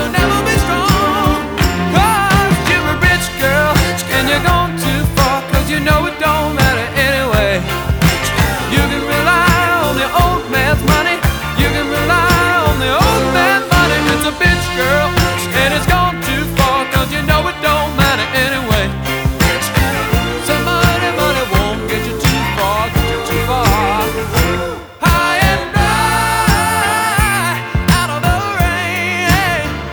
Жанр: Поп музыка / Рок / R&B / Соул